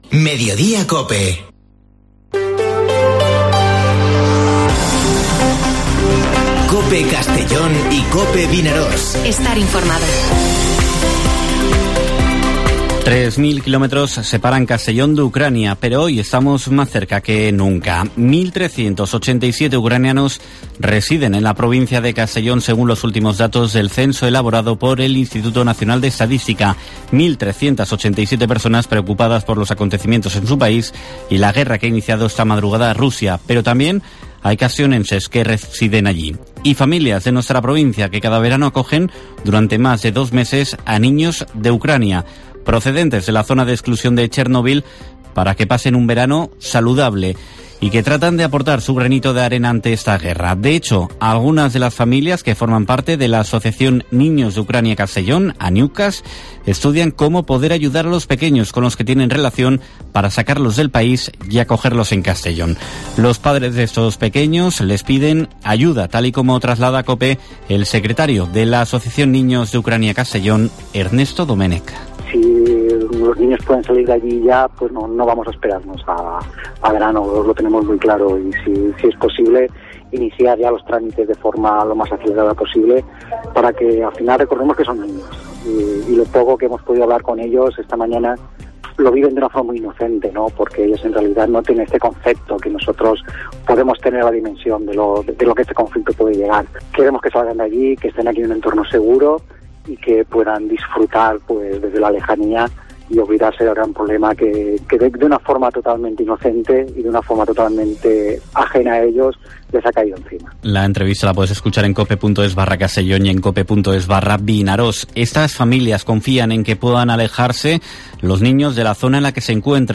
Informativo Mediodía COPE en la provincia de Castellón (24/02/2022)